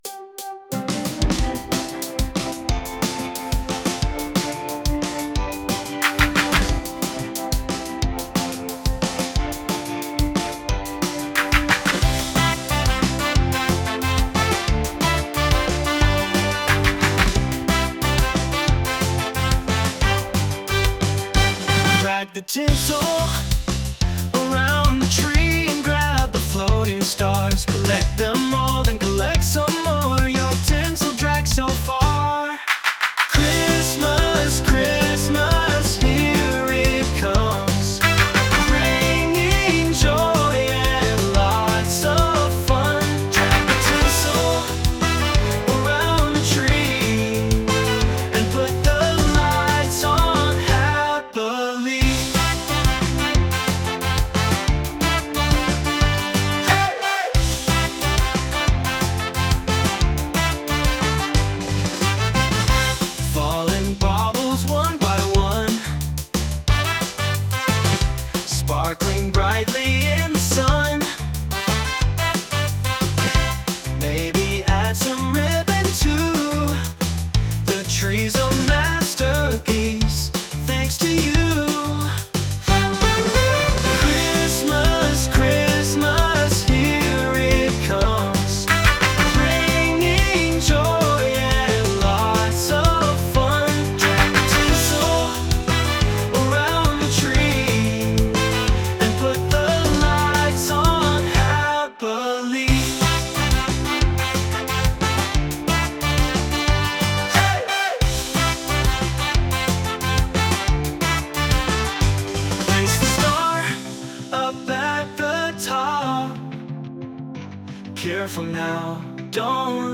Sung by Suno
Pet_Rock_(inst)-2_mp3.mp3